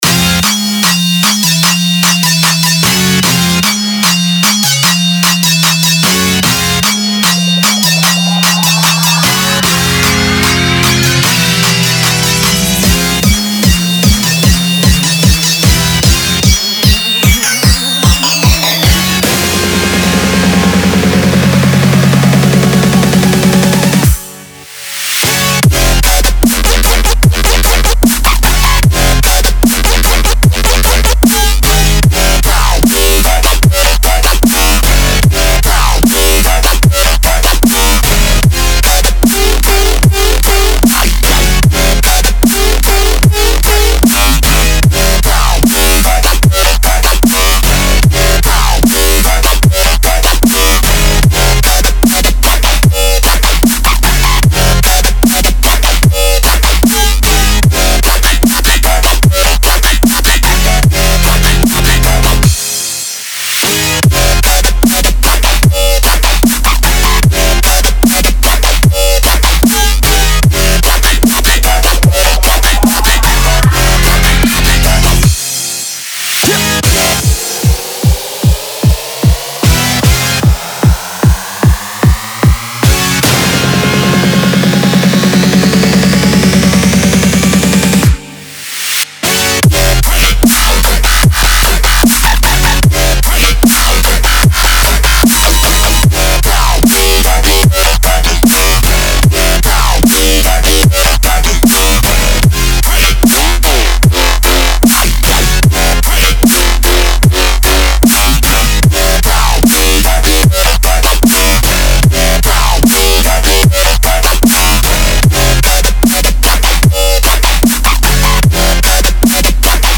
如果您正在寻找沉重的dubstep和riddim低音线，它们冲击俱乐部舞池以及Dubstep Gutter等著名的dubstep youtube频道，那么这是最适合您的选择！
• 70 Bass Presets
Drum One-Shots